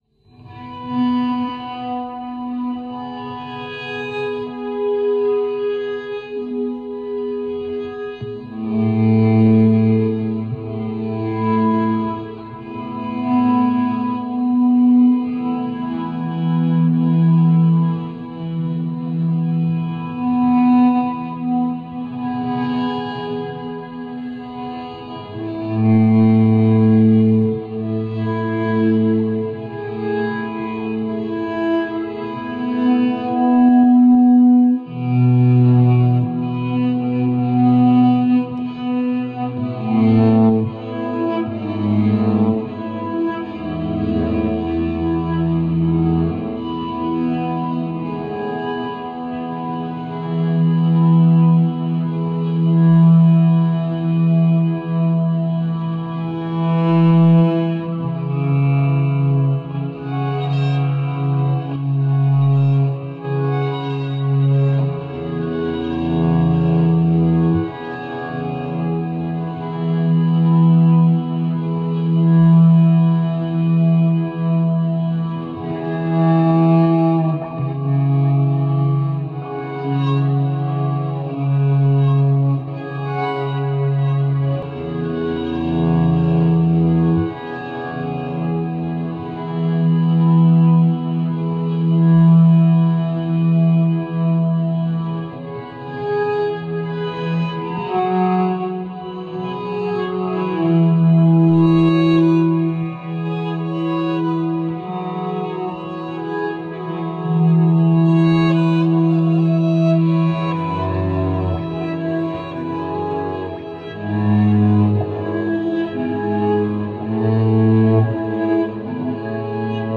for bowed electric guitar.